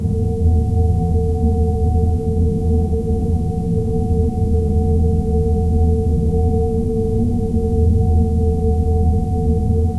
SFX: Soft room tone + dust ambience
sfx-soft-room-tone--peo57bow.wav